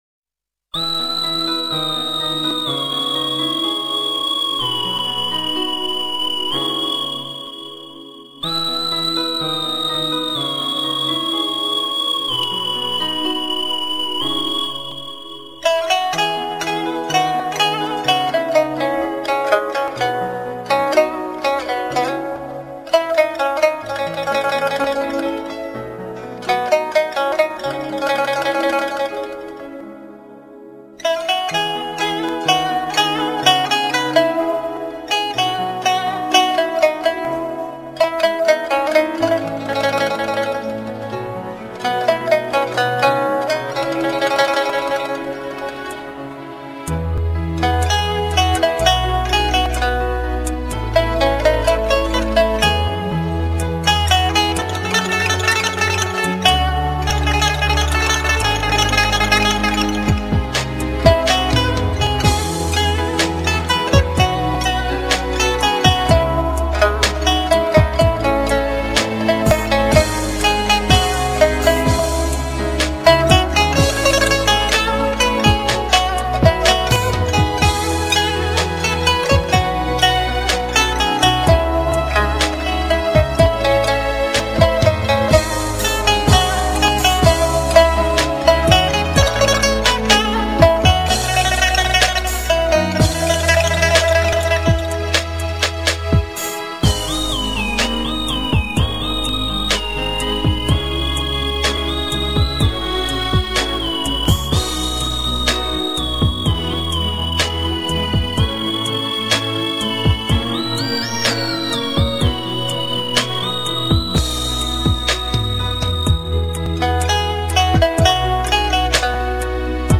琵琶独奏
精湛的琵琶演奏技艺，时而如高山流水，时而如低空流云，
左右着你的情绪，似远似近、若即若离，